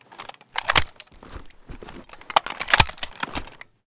vz58_reload.ogg